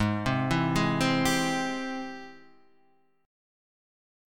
Ab+M7 Chord